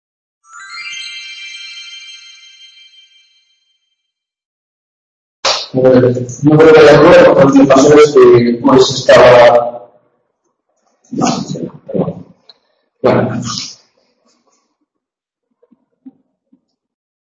1ª tutoria de Historia de la Baja Edad Moderna - Crisis del siglo XVII - 2ª parte
El vídeo de la tutoría se encuentra dividido en varios fragmentos al haberse interrumpido la grabación por problemas informáticos.